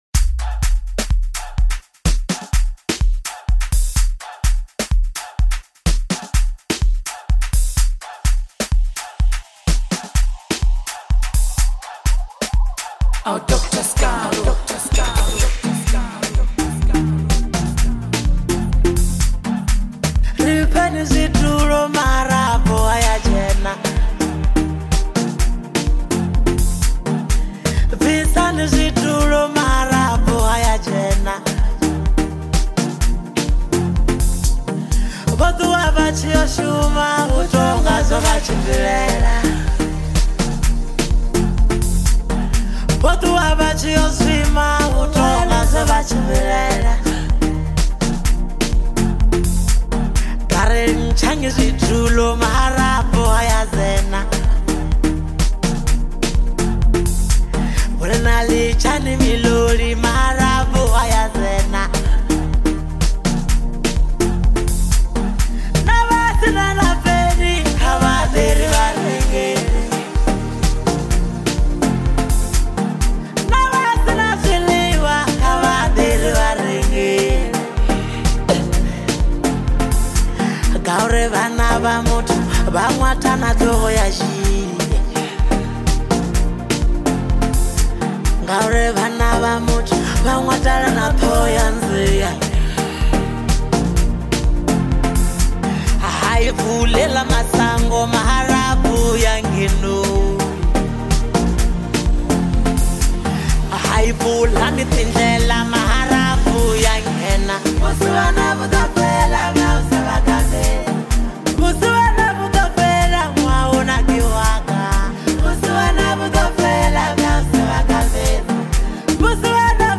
a vibrant and electrifying track